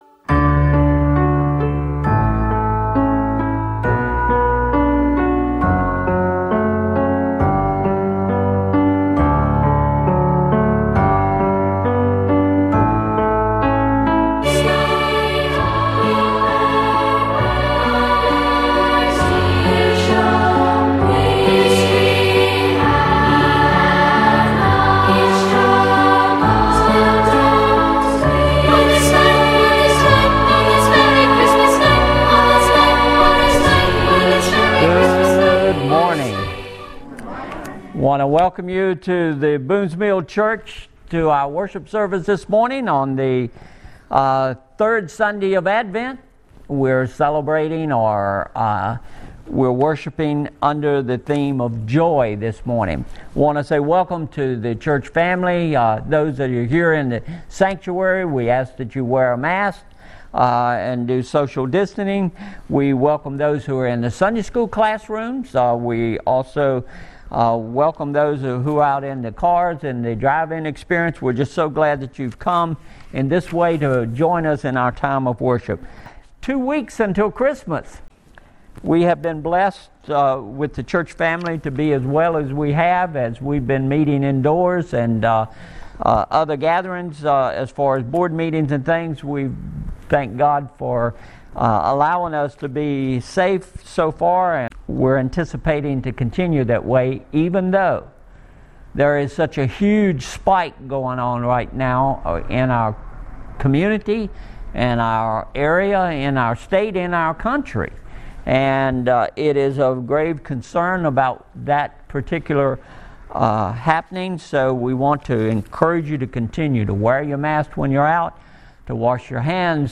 Sermon
(A first person narration/drama - by a shepherd named Jacob)